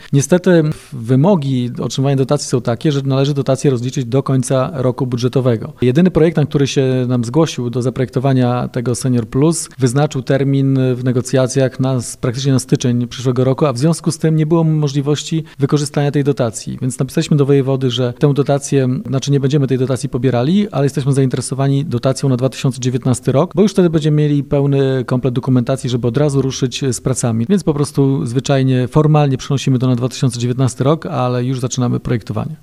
Jak twierdzi Wojciech Iwaszkiewicz, burmistrz Giżycka, w tak krótkim terminie nie ma możliwości przeprowadzenia inwestycji.